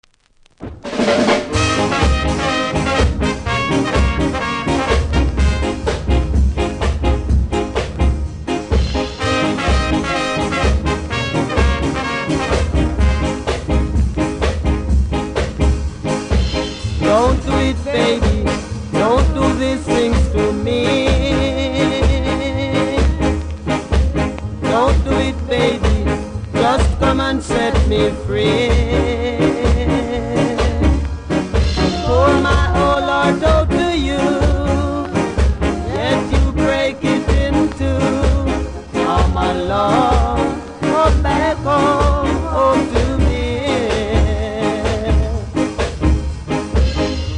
1本太いキズあり中盤何発かノイズ感じます。